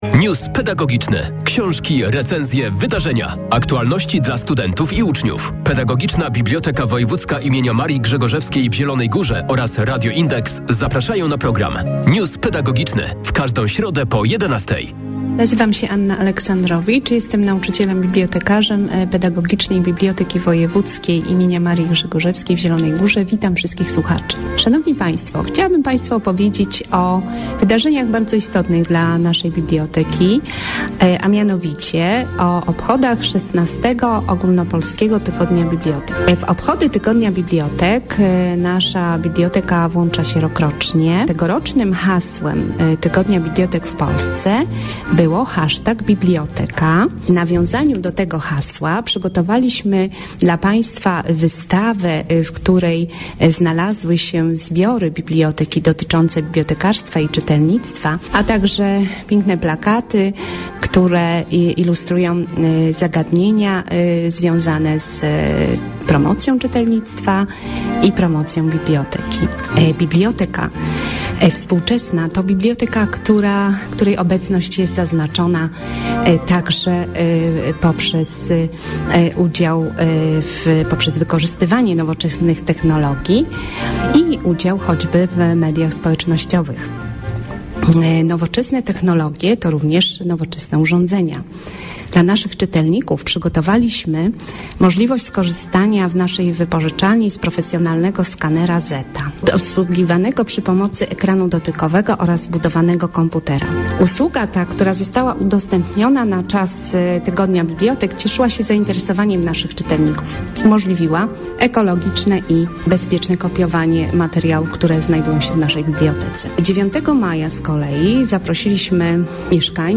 W placówce było nie tylko literacko czy naukowo, ale i technologicznie, artystycznie, historycznie, a nawet kulinarnie. News pedagogiczny.